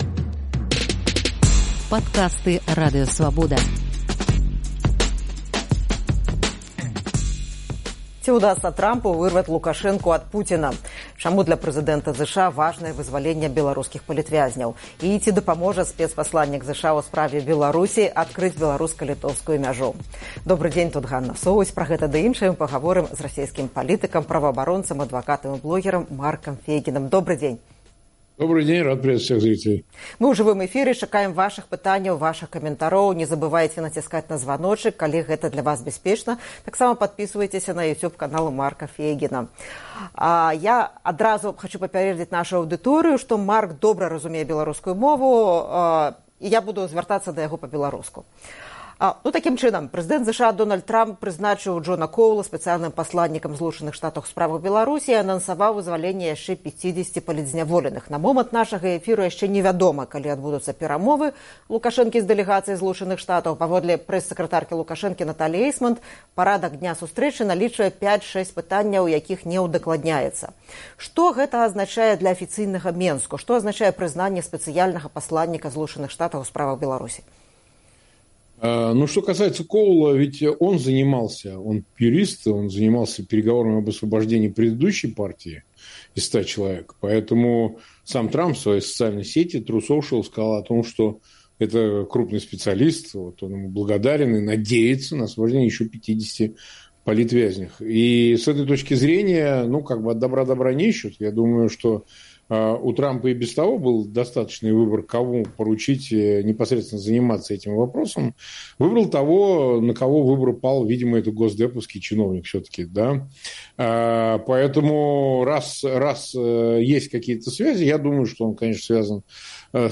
ў жывым эфіры «Піка Свабоды»